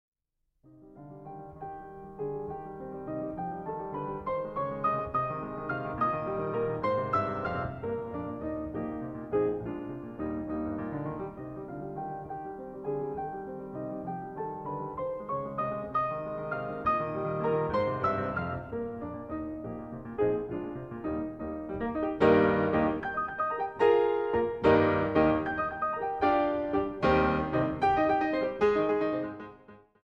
Menuetto 3:21